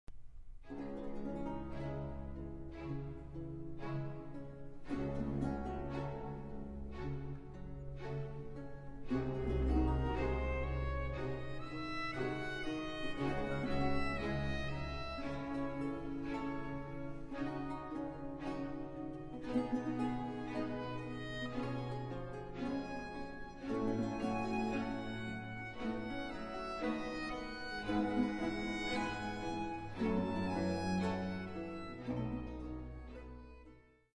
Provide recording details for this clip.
On authentic instruments.